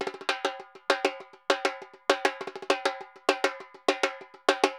Repique 1_Samba 100_1.wav